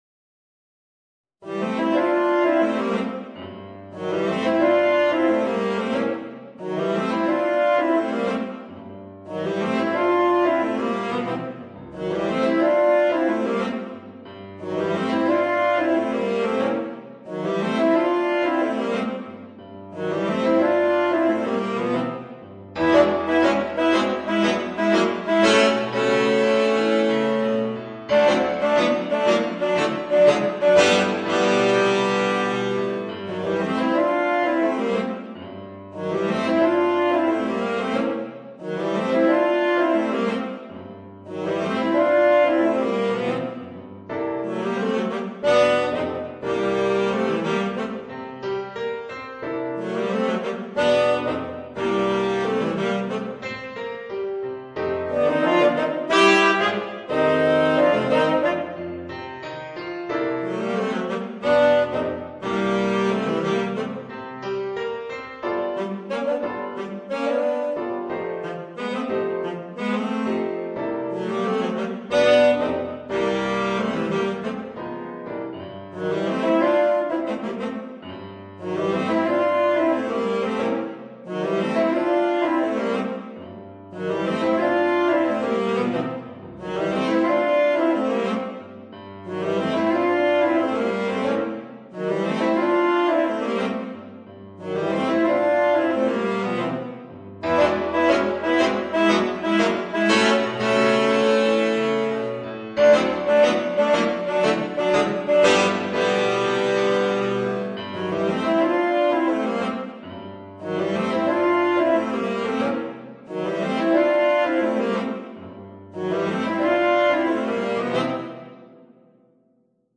Voicing: 2 Tenor Saxophones and Piano